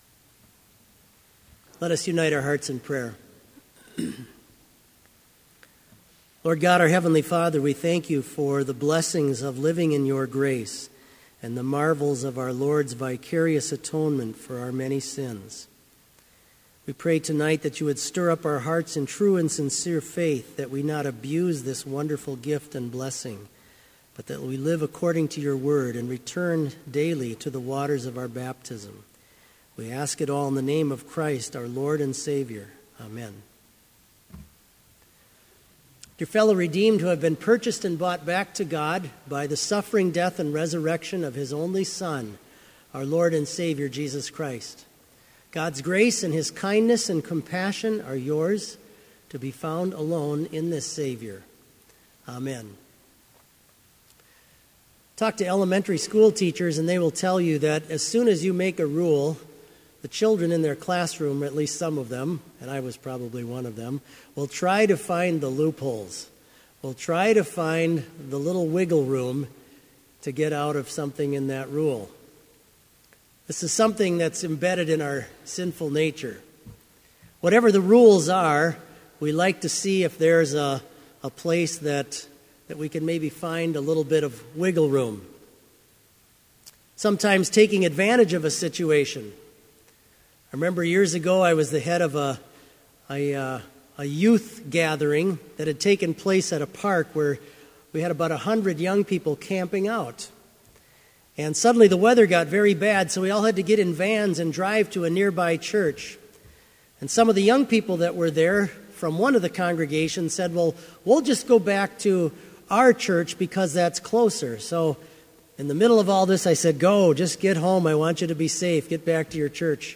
Sermon audio for Evening Vespers - October 7, 2015